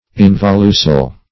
Search Result for " involucel" : The Collaborative International Dictionary of English v.0.48: Involucel \In*vol"u*cel\ (?; 277), n. [Dim. of involucre, or involucrum: cf. F. involucelle.]